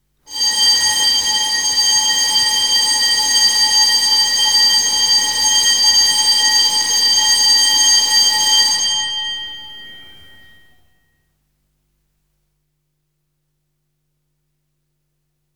JFuiBsPRvR3_sonnerie-école.wav